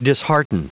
Prononciation du mot dishearten en anglais (fichier audio)